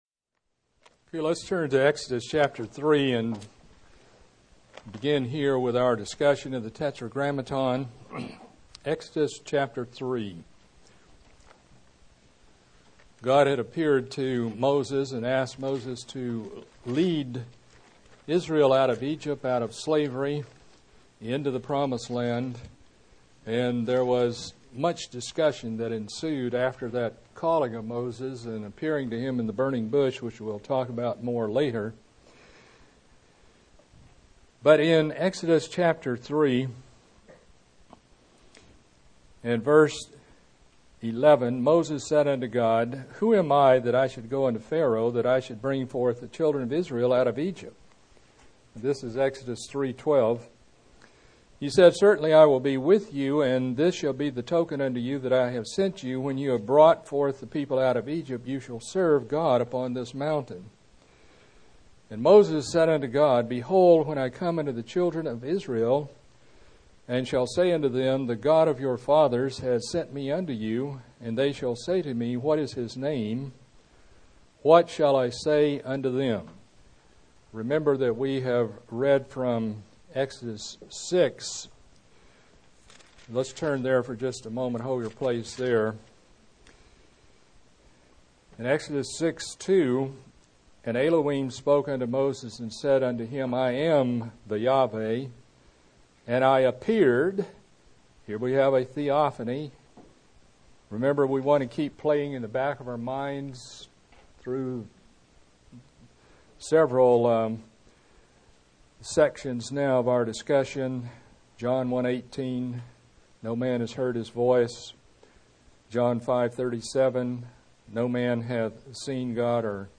During the 2007 class year we were able to record the Fundamentals of Theology class